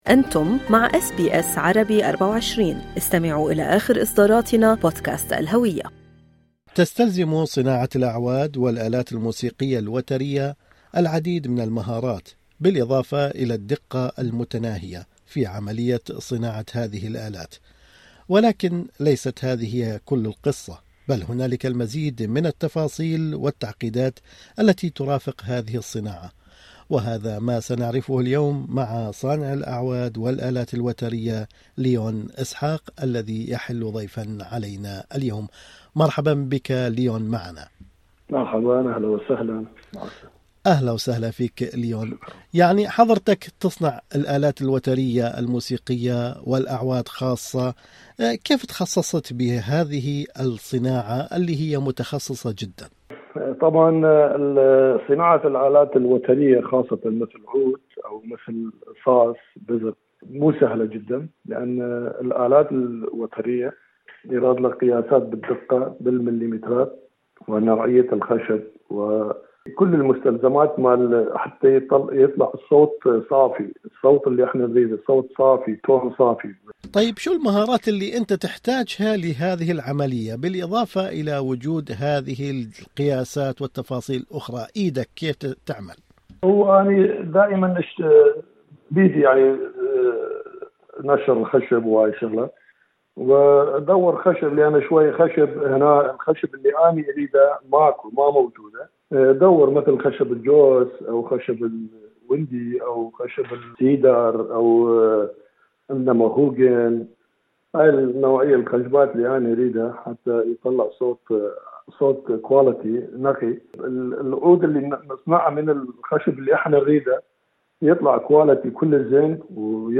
في حديث له مع أس بي أس عربي